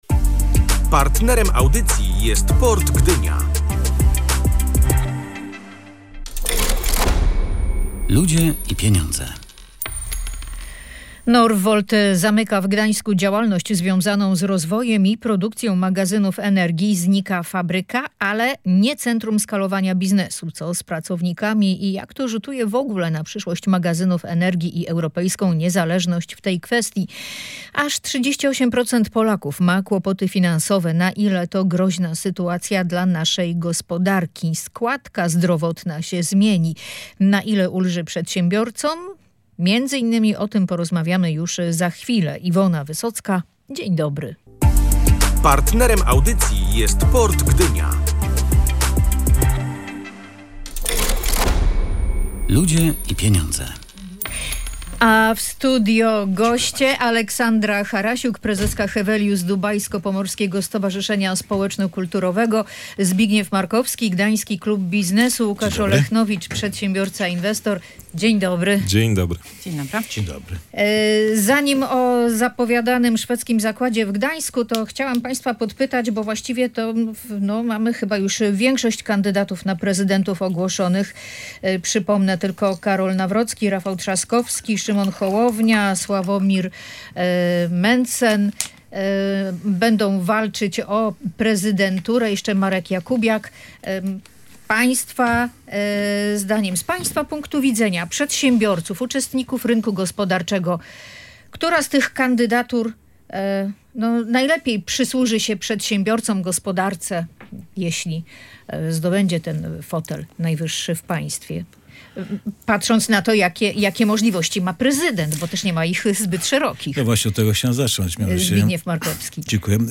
Rafał Trzaskowski, Karol Nawrocki, Szymon Hołownia, Sławomir Mentzen i Marek Jakubiak – to już pewni kandydaci, którzy powalczą w wyborach prezydenckich. O tym, który z nich byłby najlepszy z punktu widzenia przedsiębiorców, dyskutowali goście audycji „Ludzie i Pieniądze”.